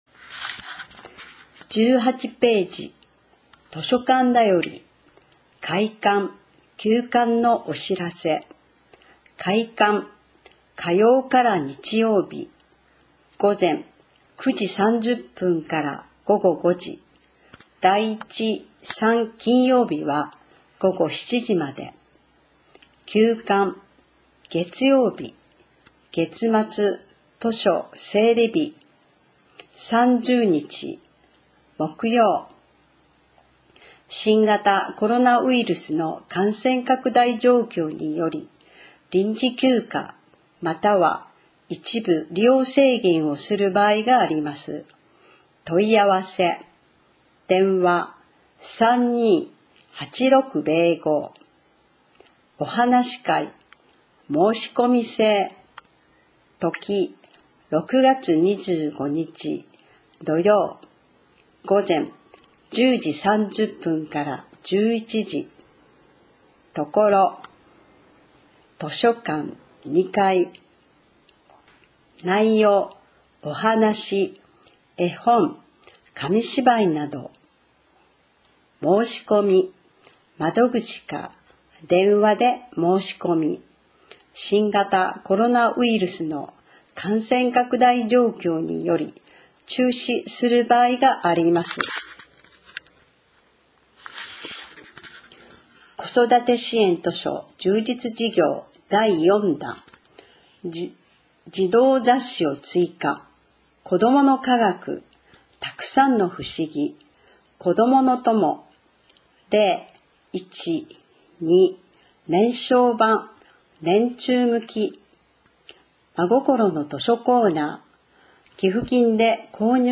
広報「かわい」令和4年度 声の広報